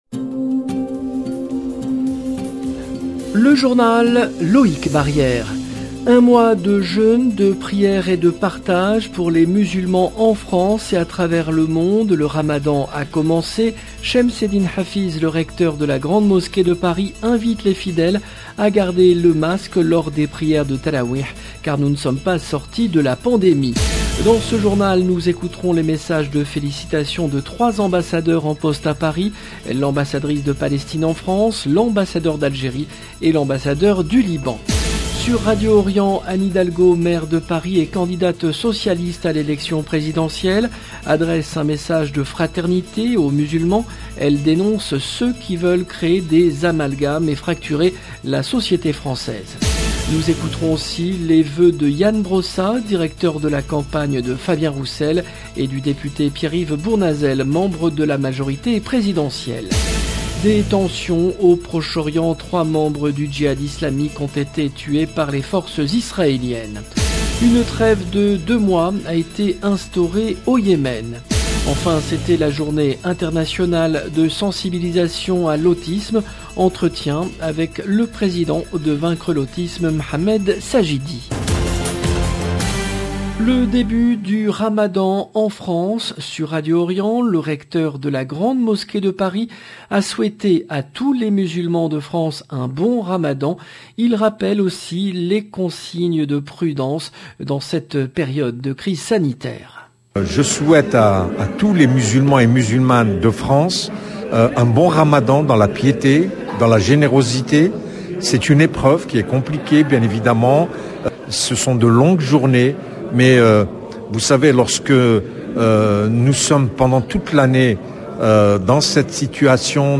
Une trêve de 2 mois décidée au Yemen. Enfin c’était la journée internationale de sensibilisation à l’autisme. Entretien